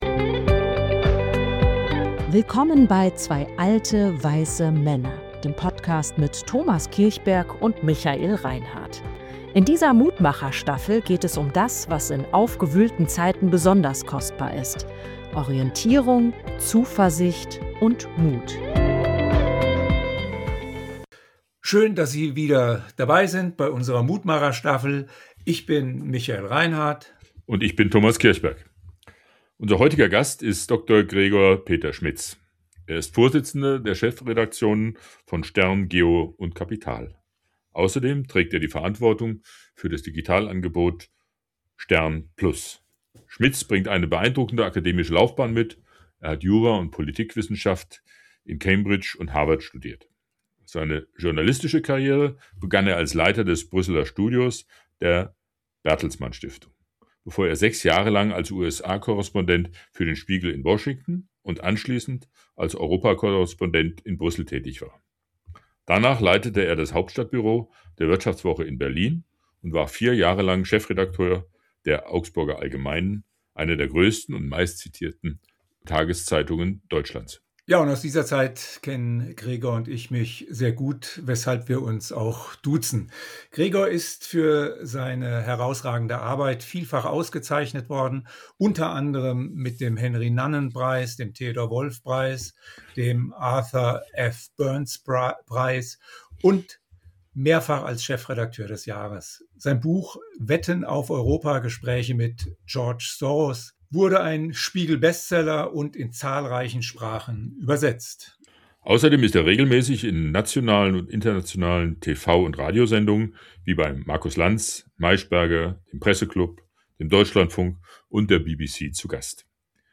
In dieser Folge unserer Mutmacher-Staffel sprechen wir mit Gregor Peter Schmitz, Chefredakteur des stern, über die wachsende Polarisierung in westlichen Demokratien, die wirtschaftlichen Herausforderungen des Journalismus und die Frage, wie Medien ihrer demokratischen Verantwortung gerecht bleiben können.